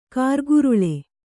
♪ kārguruḷe